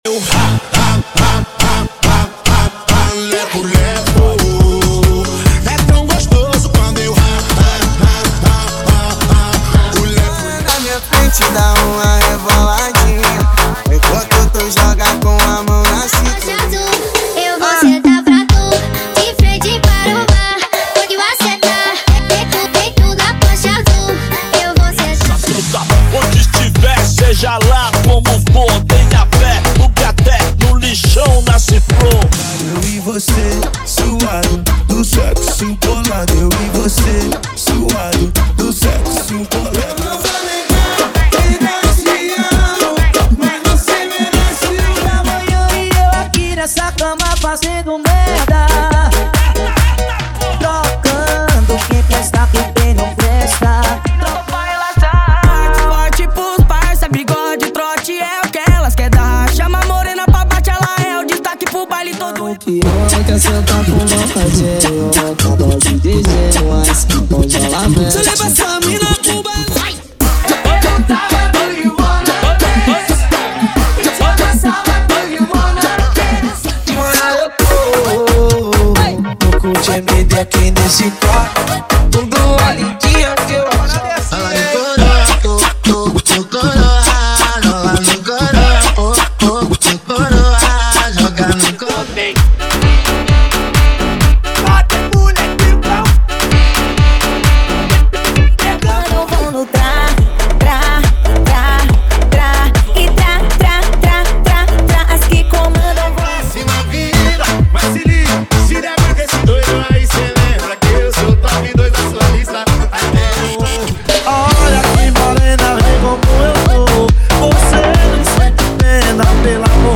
• Funk Light e Funk Remix = 135 Músicas